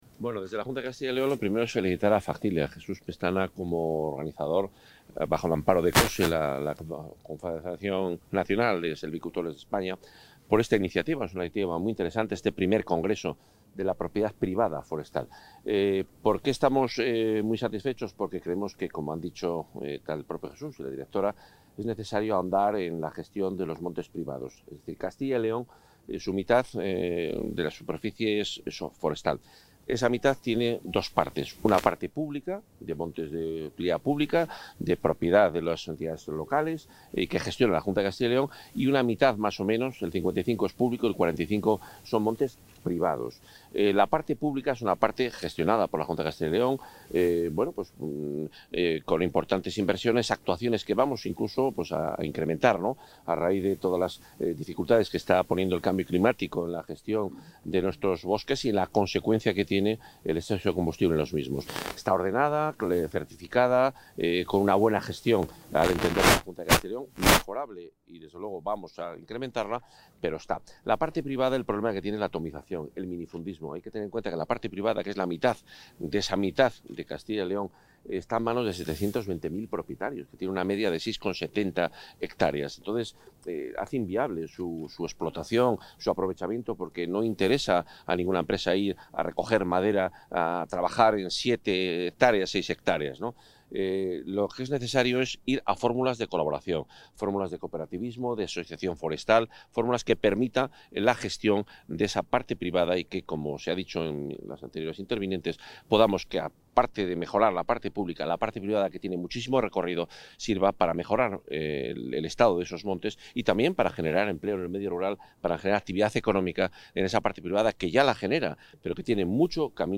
Intervención del consejero.
El consejero de Medio Ambiente, Vivienda y Ordenación del Territorio, Juan Carlos Suárez-Quiñones, ha inaugurado hoy en Valladolid el I Congreso Nacional de la Propiedad Forestal Privada, donde ha resaltado la importancia del sector forestal en Castilla y León y que su puesta en valor e impulso como herramienta de generación de riqueza y empleo ocupa una posición central entre las actuaciones que integran la política medioambiental de la Junta, orientada a la protección, la mejora y dinamización del sector forestal, la lucha contra las plagas y los incendios forestales, y la movilización de lo